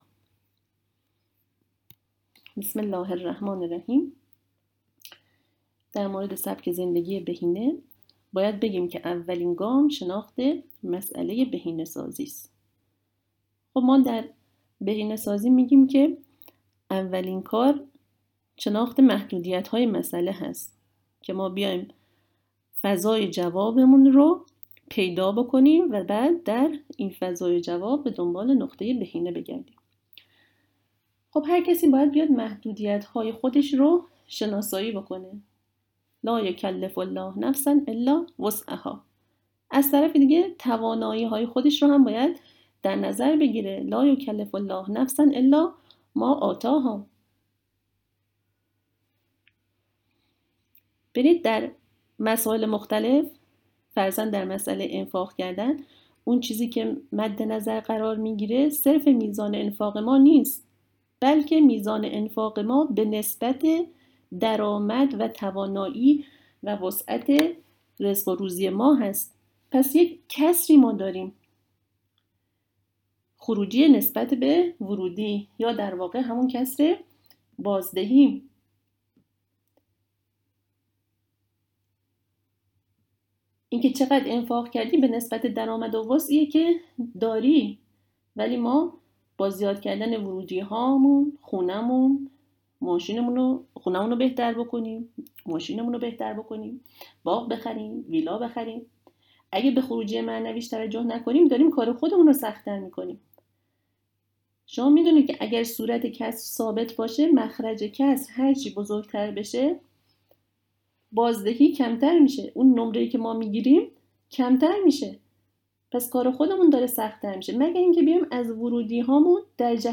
آیات و احادیث بکار رفته در سخنرانی سبک زندگی بهینه شیعیان- قسمت اول: